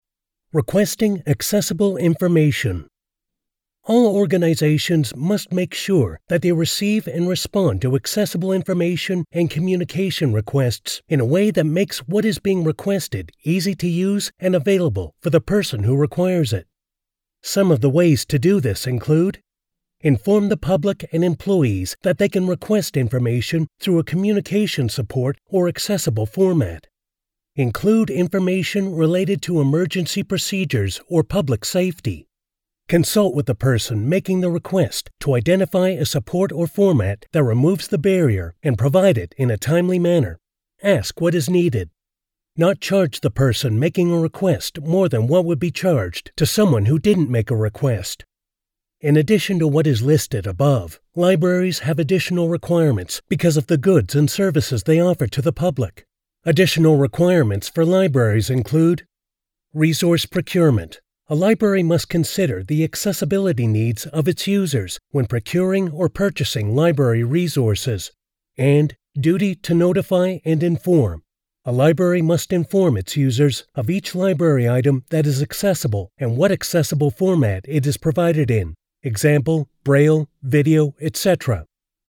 Listen Back Play Pause Stop Forward 00:00 00:36 Mute Unmute Audio narration.